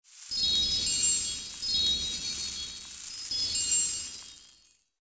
AA_heal_pixiedust.ogg